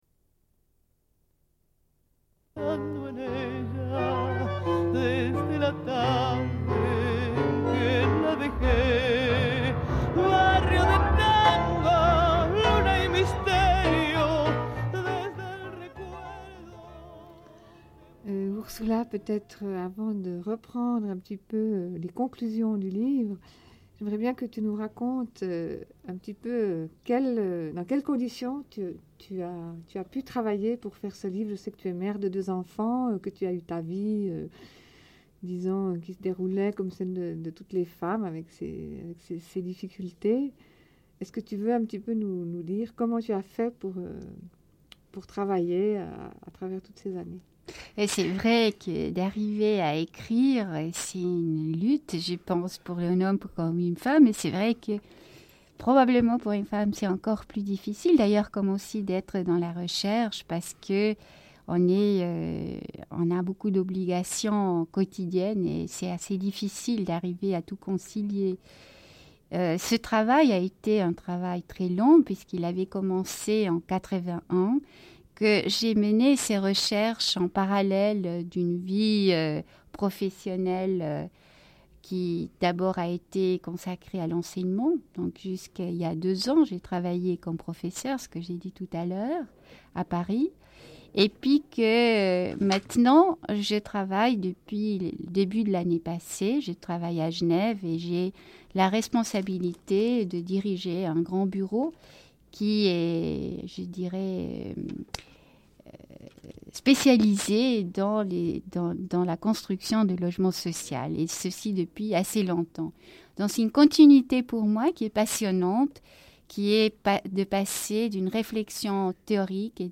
Une cassette audio, face B31:35
Elles sont toutes les deux architectes.